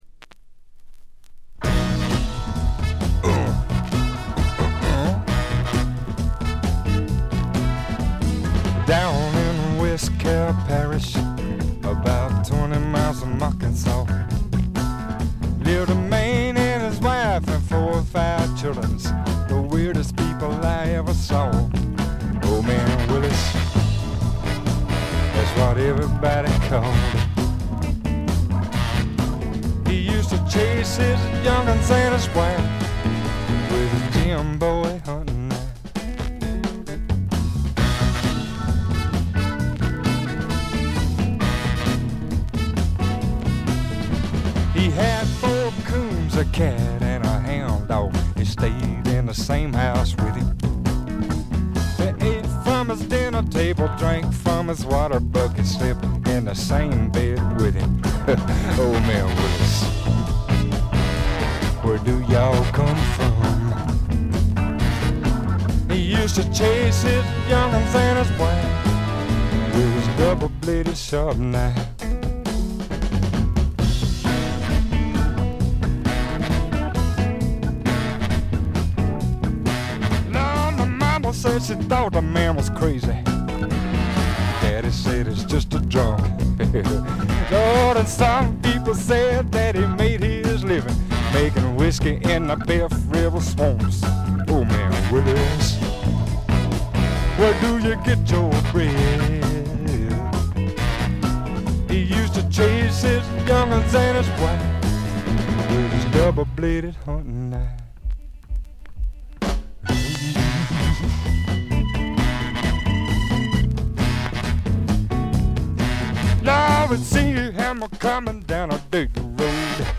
ところどころでチリプチ。散発的なプツ音。
試聴曲は現品からの取り込み音源です。